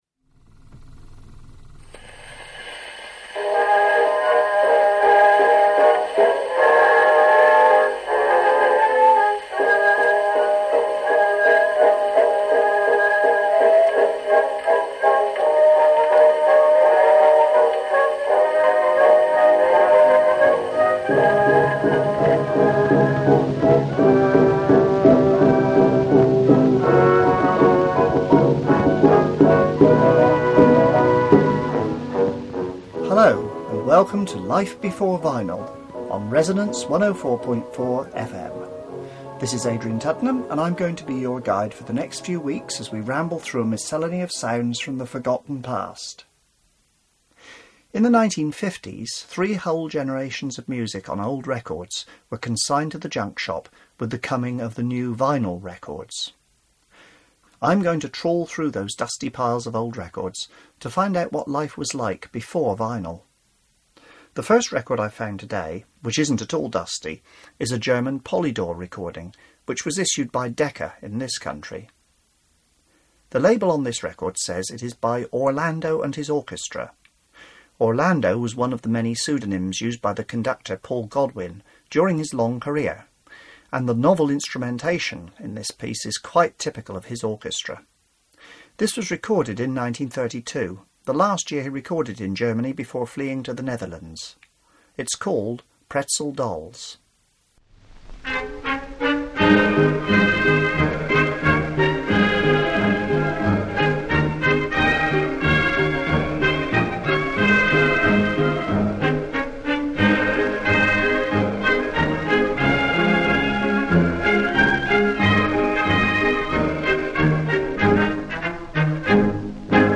Billy Whitlock, prolific composer of novely pieces and laughing songs - The mobile recording van and it's shortcomings - A brilliant endless banjo performance which tests the endurance of the piano accompanist - A thrilling ghost story first broadcast in 1927